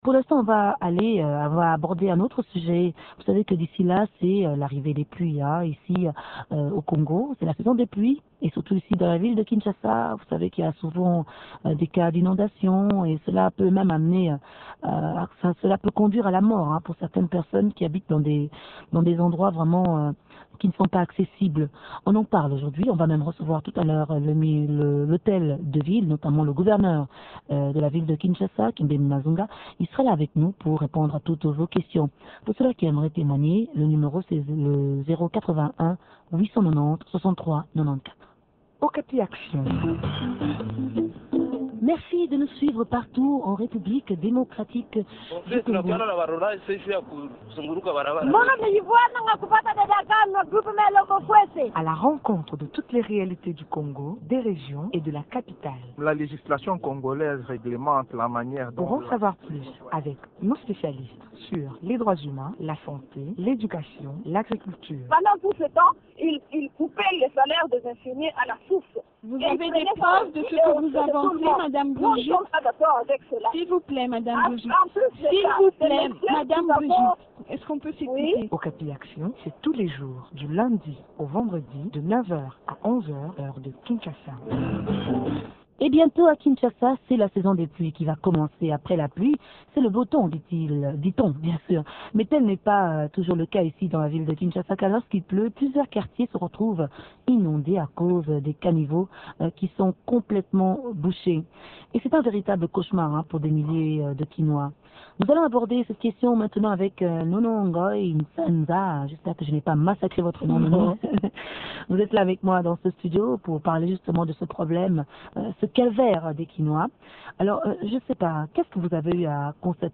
Suite à toutes ses expériences passées, quelles sont les mesures prises pour pallier toutes ces situations ? Kibembe Mazunga gouverneur de la ville de Kinshasa